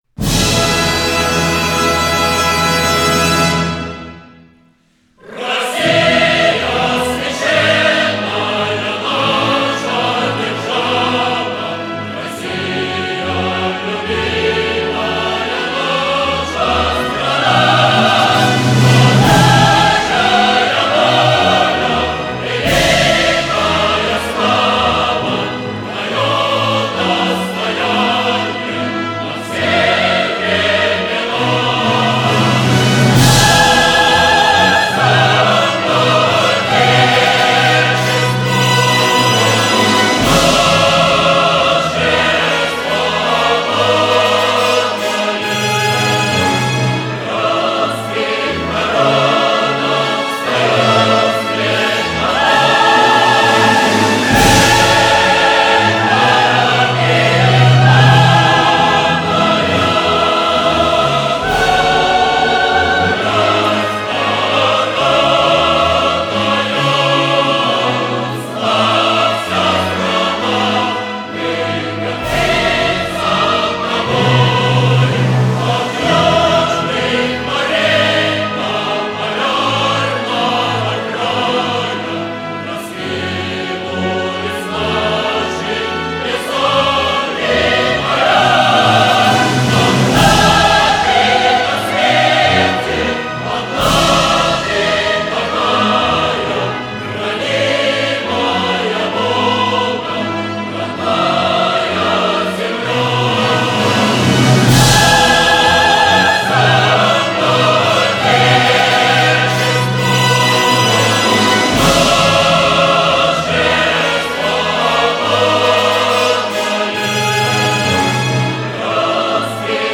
Gimn_Rossiiso_slovami_mp3store.mp3